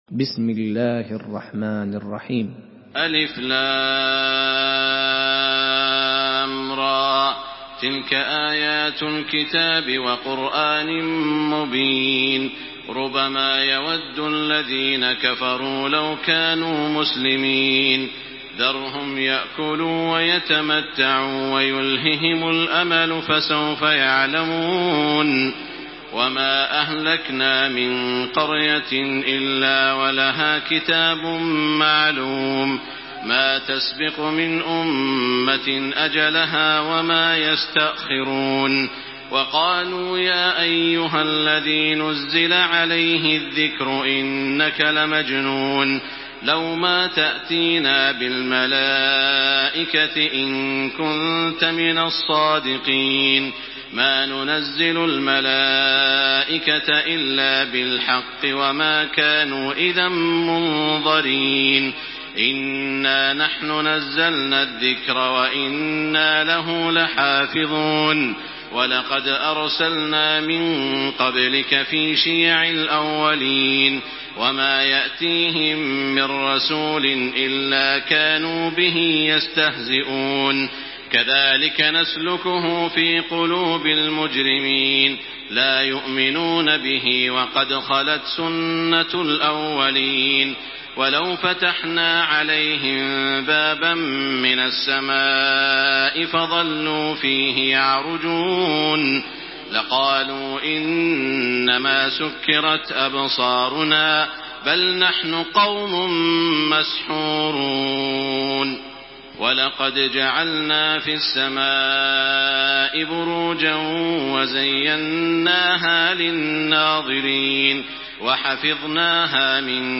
Listen and download the full recitation in MP3 format via direct and fast links in multiple qualities to your mobile phone.
دانلود سوره الحجر توسط تراويح الحرم المكي 1428